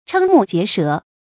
chēng mù jié shé
瞠目结舌发音
成语正音结，不能读作“jiē”。